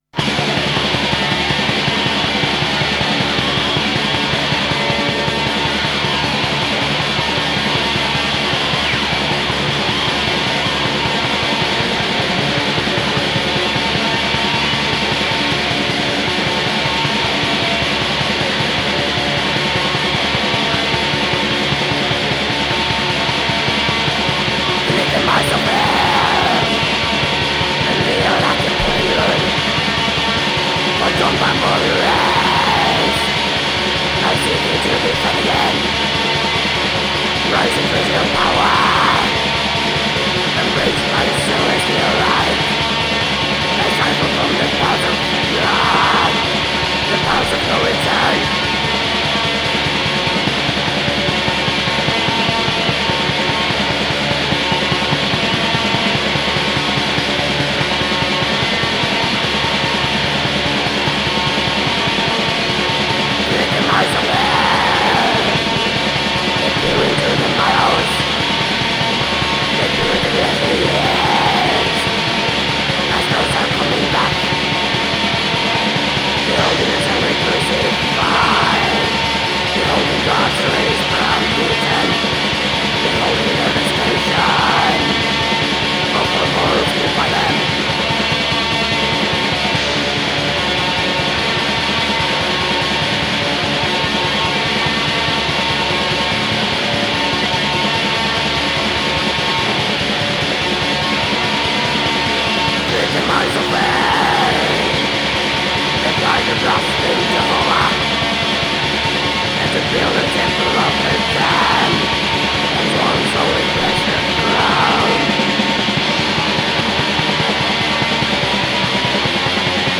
یک البوم بسیار تاریک و خشن و اتمسفریک
بلک متال